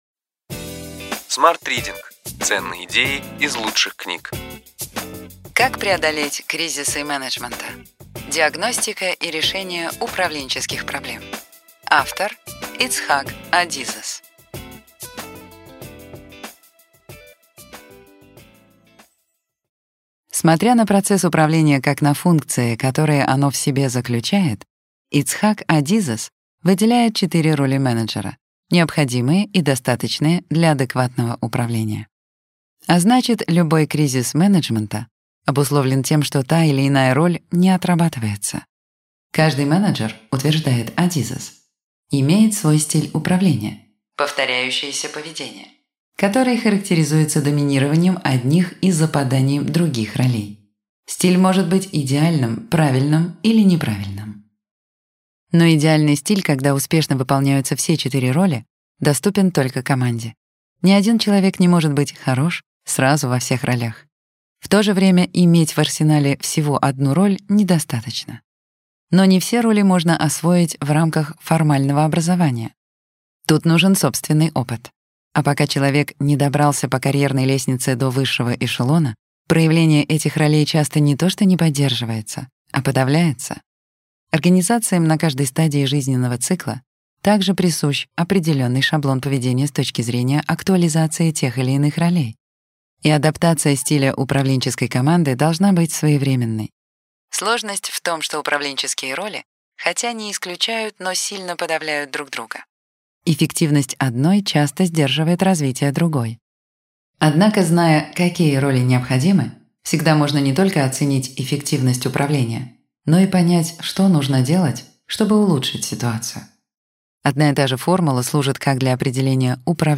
Аудиокнига Ключевые идеи книги: Как преодолеть кризисы менеджмента. Диагностика и решение управленческих проблем. Ицхак Адизес | Библиотека аудиокниг